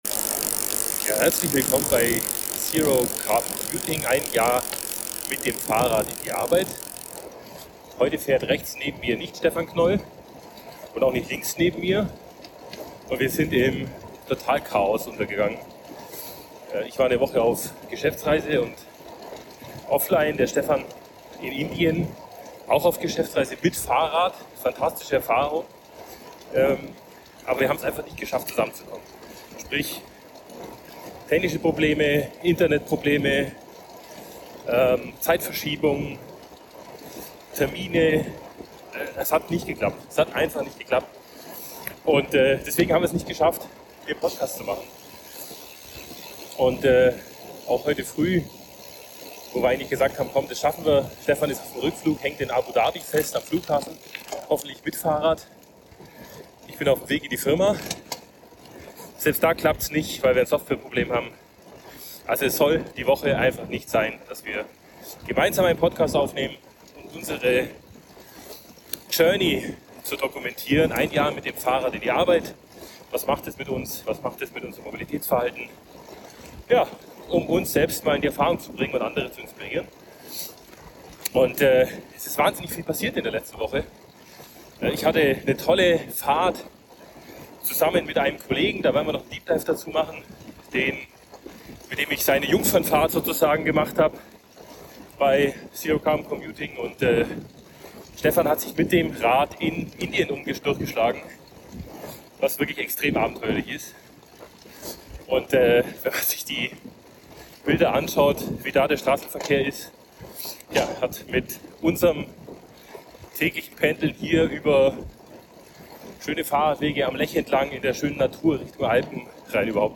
#25 - Technische Probleme, gestrandeten in Indien und miserable Tonqualität